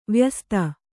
♪ vyasta